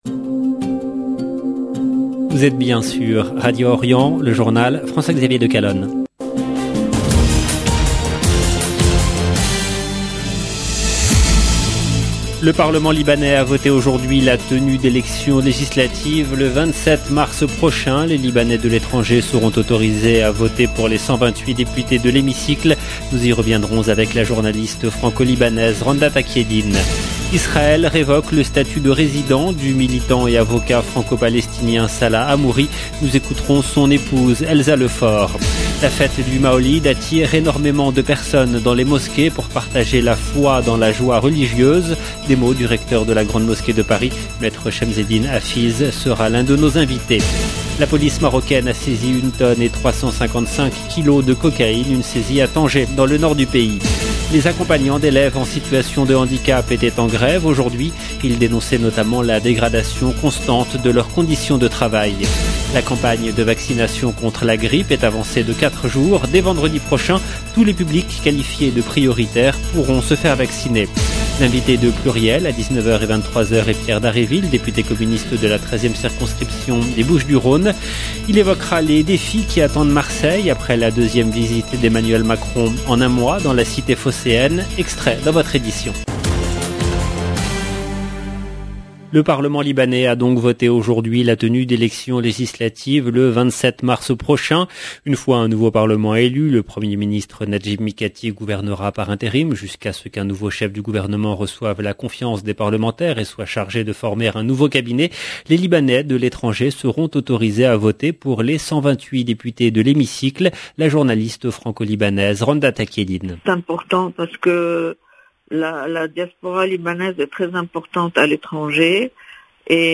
EDITION DU JOURNAL DU SOIR EN LANGUE FRANCAISE